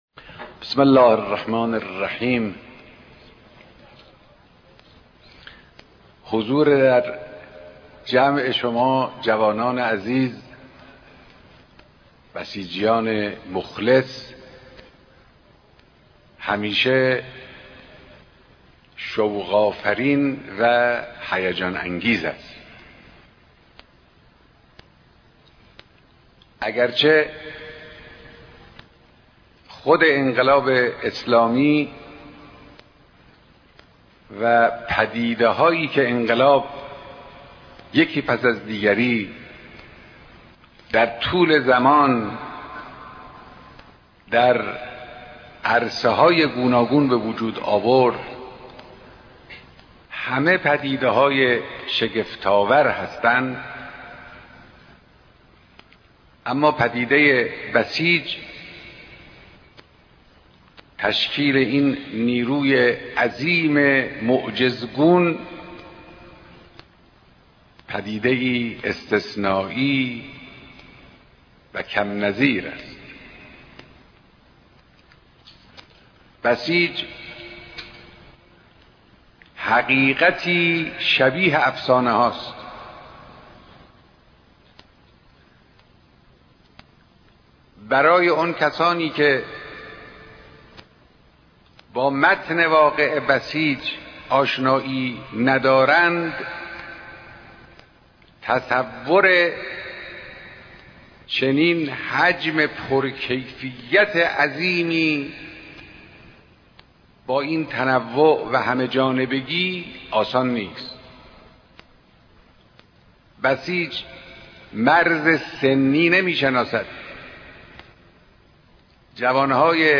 مراسم نظامي رده هاي مختلف نيروي مقاومت بسيج